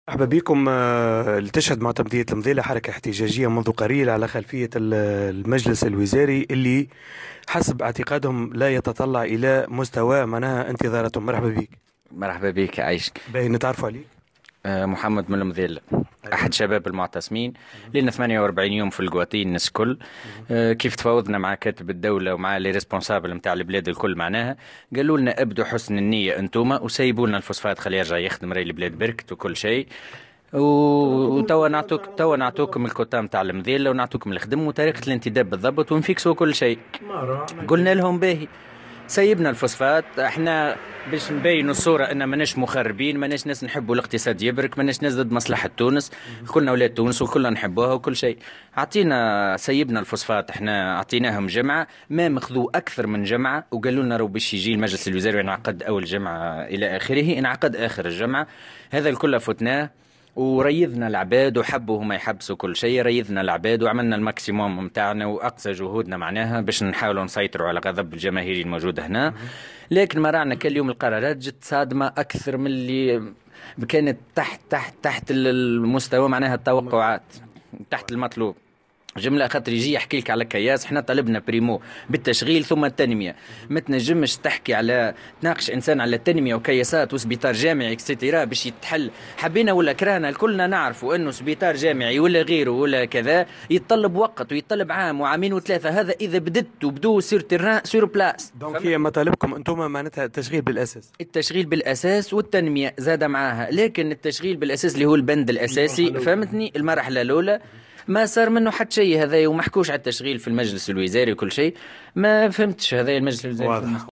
تصريح أحد المحتجين لمراسل الجوهرة أف أم تحميل المشاركة علي